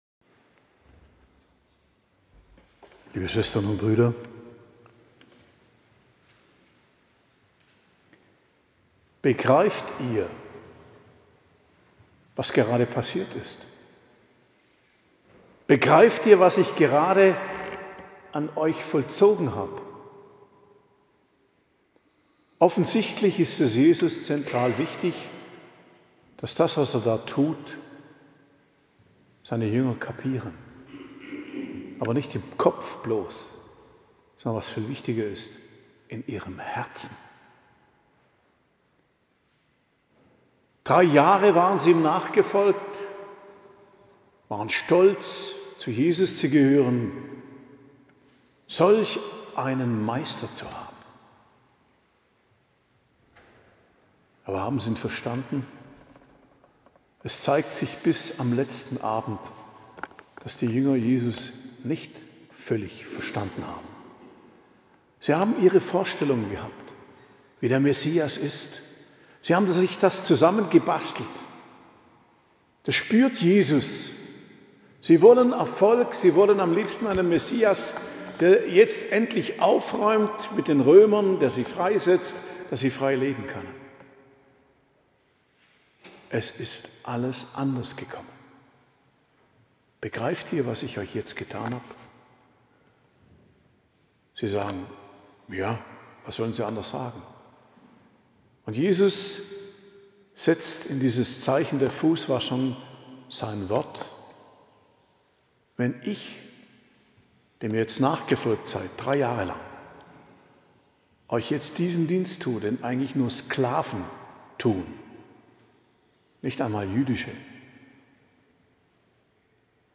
Predigt am Gründonnerstag, 17.04.2025 ~ Geistliches Zentrum Kloster Heiligkreuztal Podcast